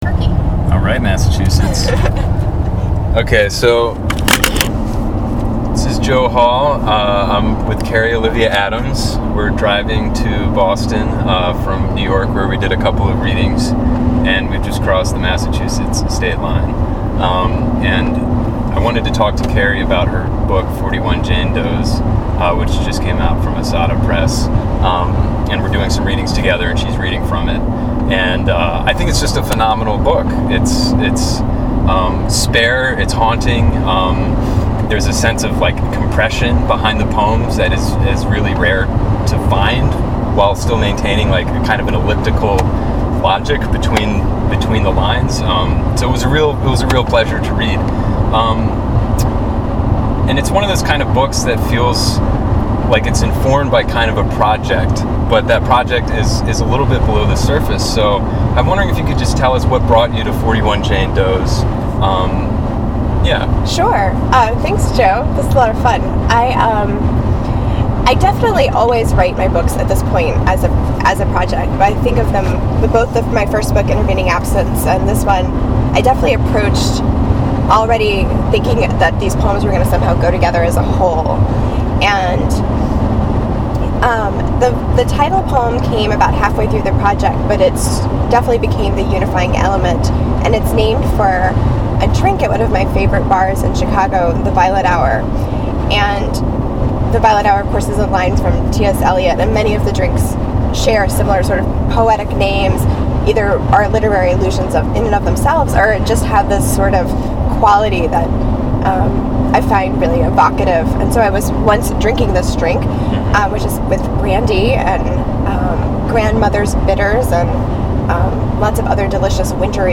We drove to Boston together and did this little interview about her book.